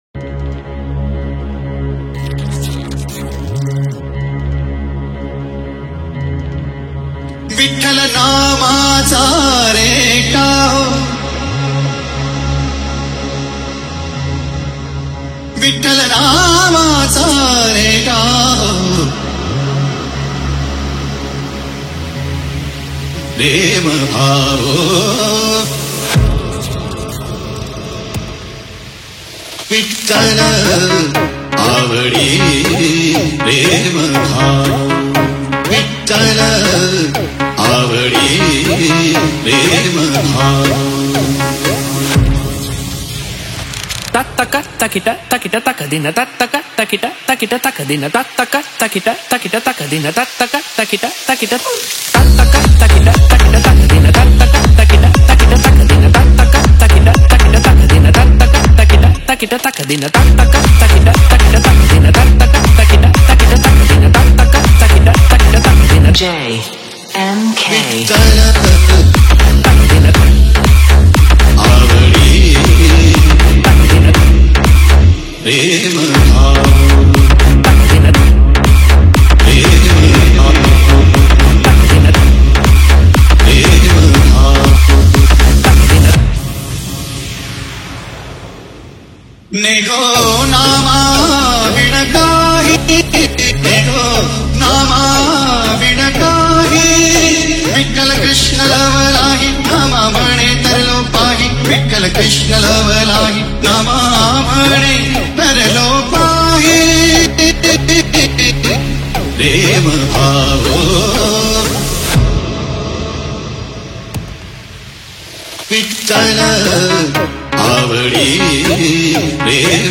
• Category:Marathi Sound Check